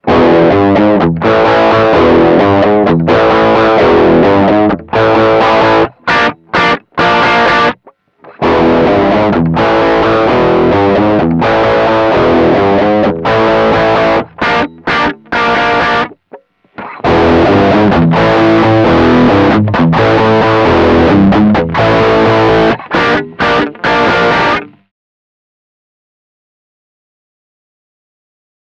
• US – glatter Frequenzverlauf
US: Clean, Crunch, Heavy
tb__ppamk2__us__05__cln-crn-hvy.mp3